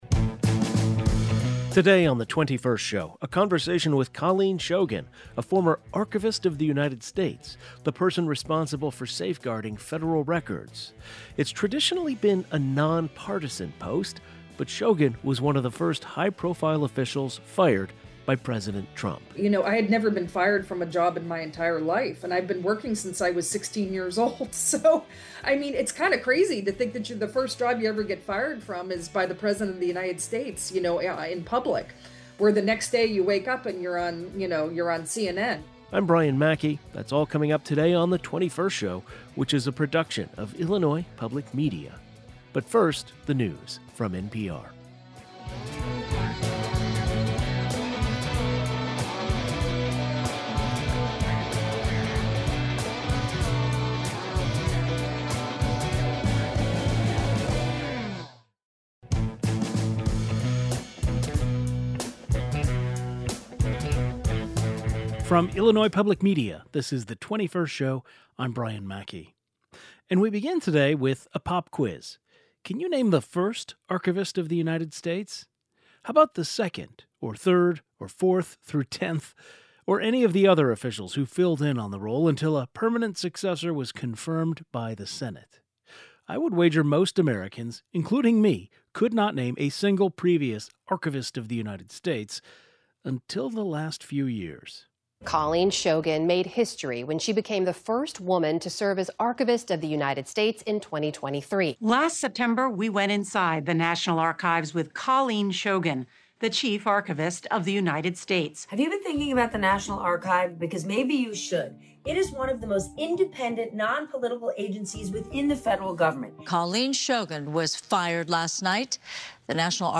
Today's show included a rebroadcast of the following "best of" segment first aired October 15, 2025: Former Archivist of the U.S. Colleen Shogan on preserving historic documents, facing public pressure, and being fired by President Trump.